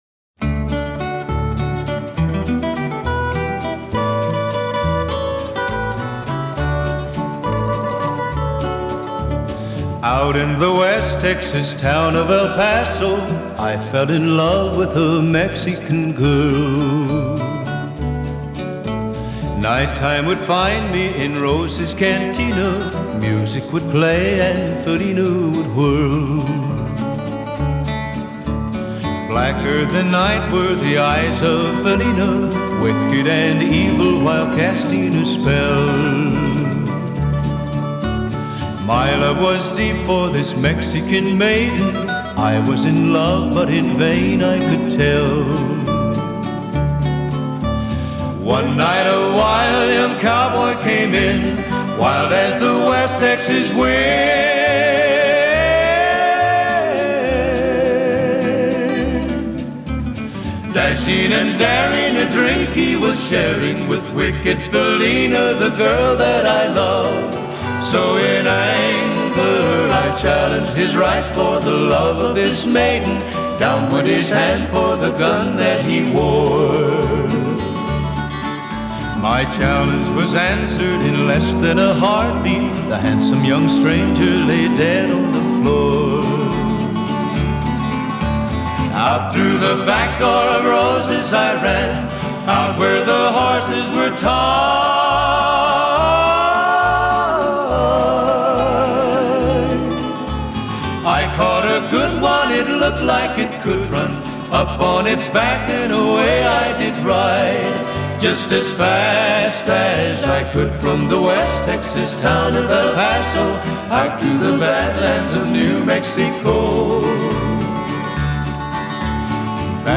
(Country Western)